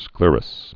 (sklîrəs, sklĕr-)